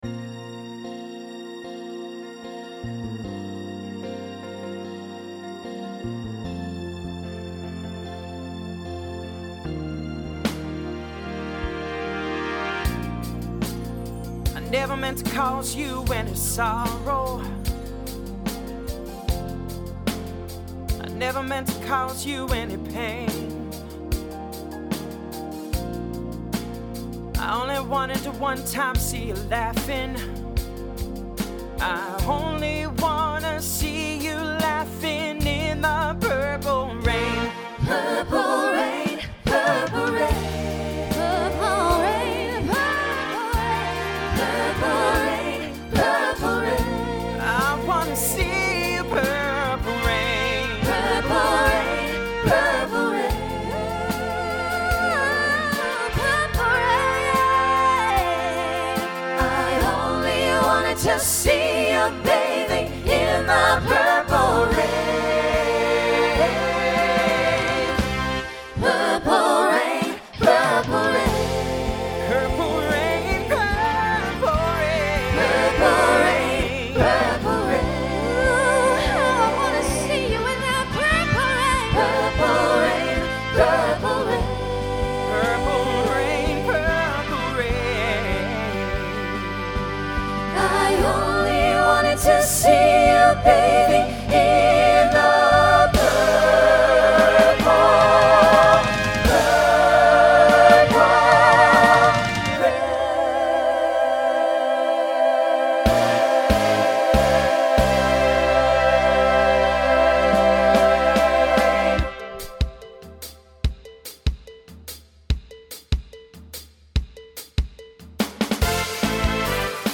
Voicing Mixed Instrumental combo Genre Pop/Dance , Rock